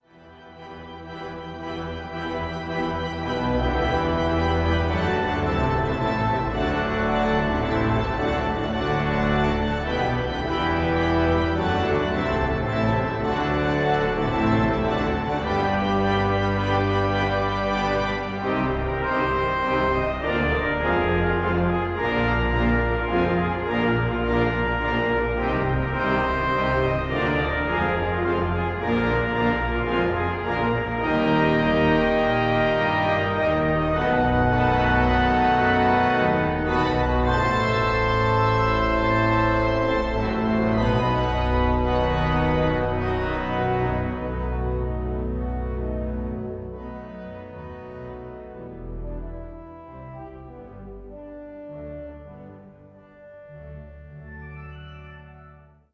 24 bit stereo
Recorded 29-30 July 2014 at Usher Hall, Edinburgh, Scotland